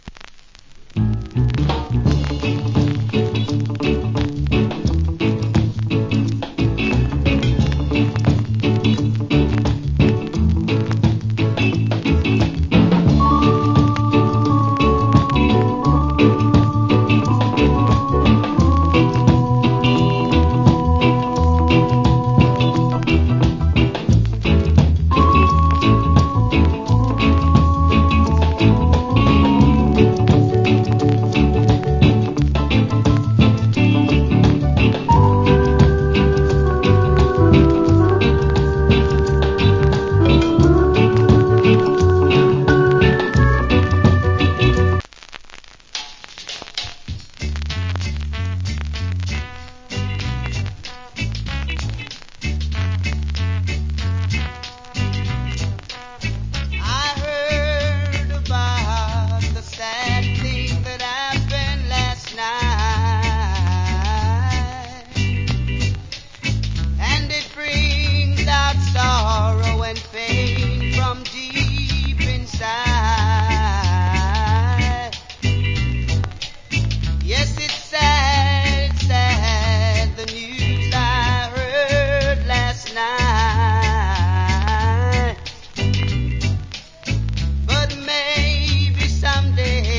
Nice Reggae Inst.